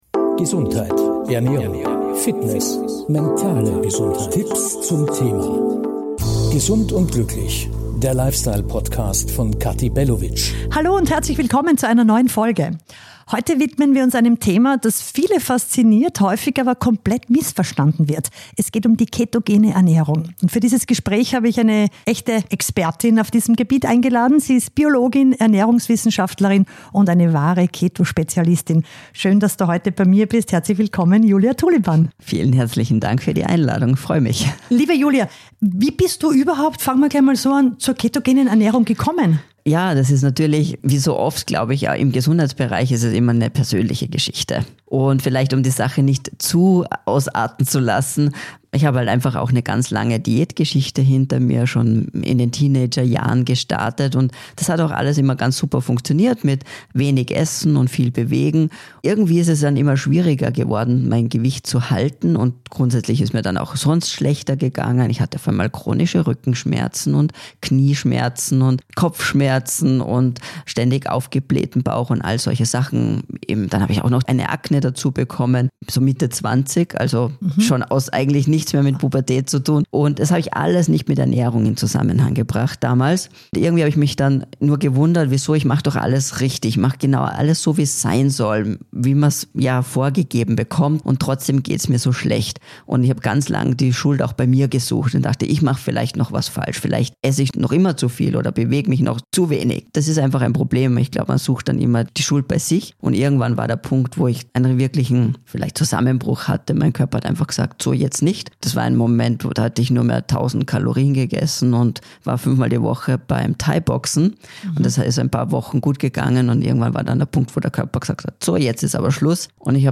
In der neuen Folge spreche ich mit der Biologin und Ernährungswissenschaftlerin